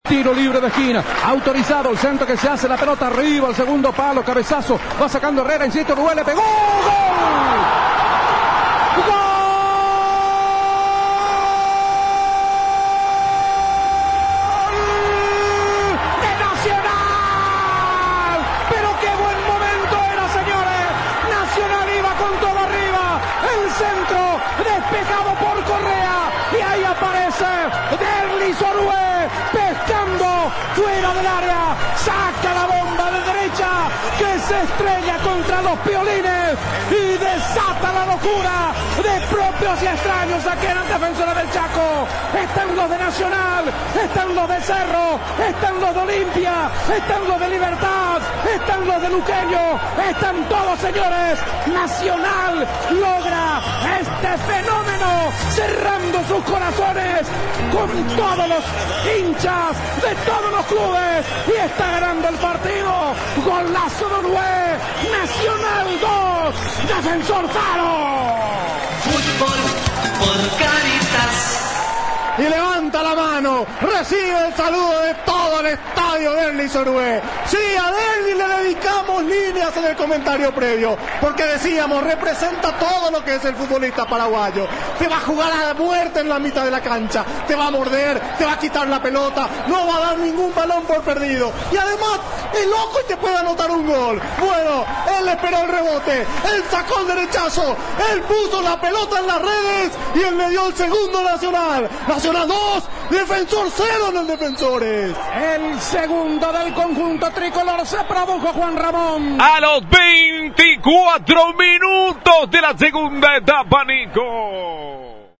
2.-goool-de-nacional-orue-INFO.mp3